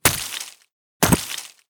Tiếng Xẻng đập vào Băng Tuyết, vỡ tan
Thể loại: Tiếng động
tieng-xeng-dap-vao-bang-tuyet-vo-tan-www_tiengdong_com.mp3